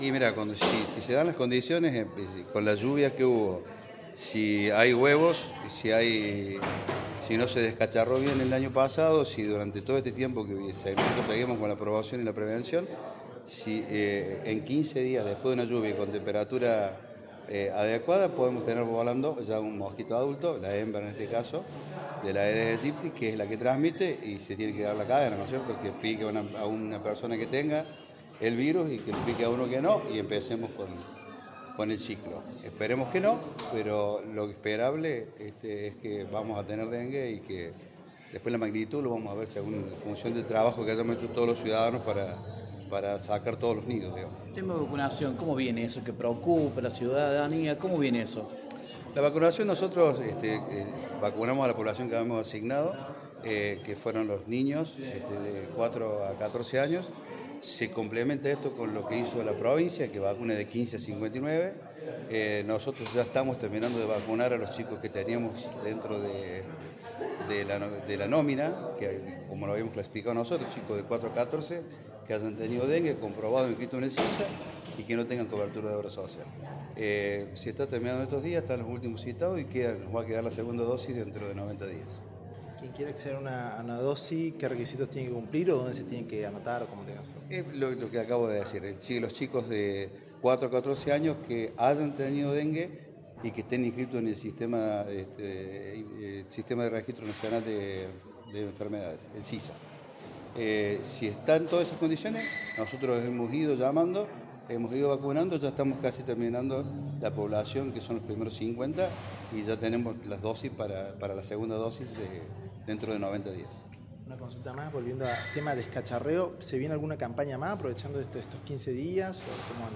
El secretario de Salud de Villa María, César Rivera, dialogó con los medios sobre distintos temas vinculados a su área, aunque el más preocupante está relacionado con el dengue, enfermedad que preocupó a todo el país el último verano y podría volver a aparecer con nuevos casos en las próximas semanas.